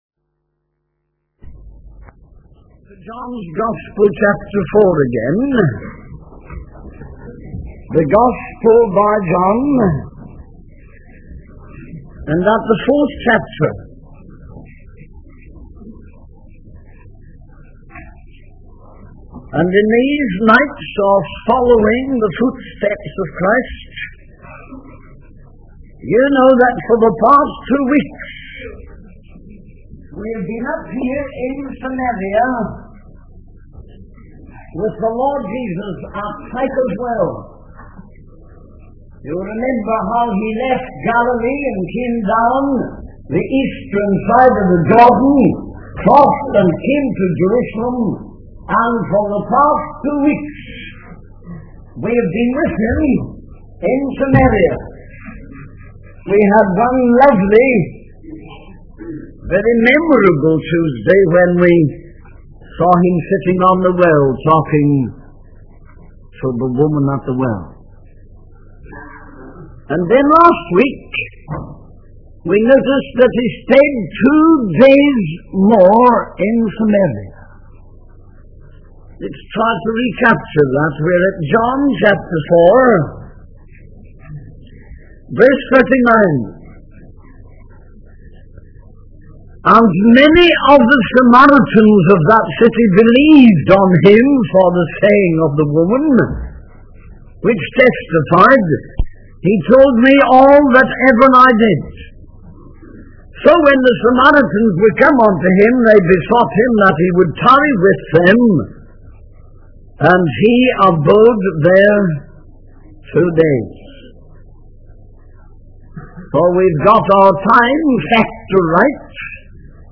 In this sermon on John's gospel chapter 4, the preacher reflects on the events that took place in Samaria, where Jesus spoke to the woman at the well.